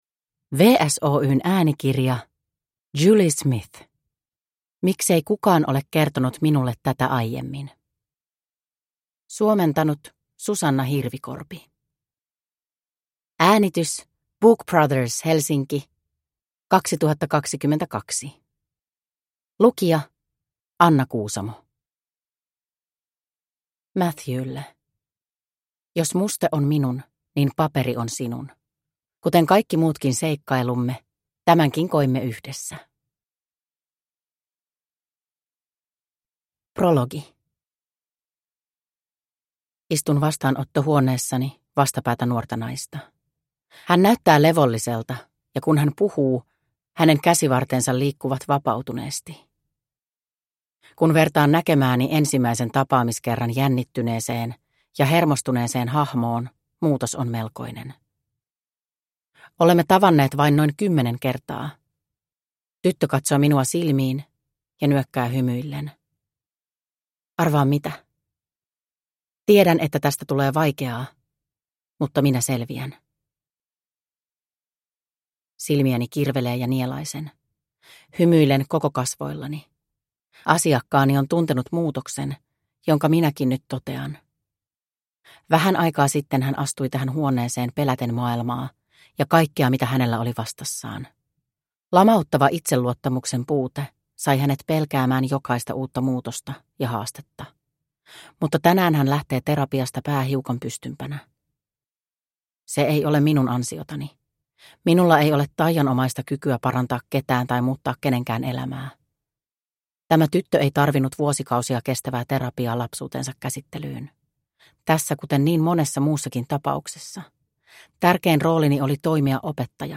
– Ljudbok – Laddas ner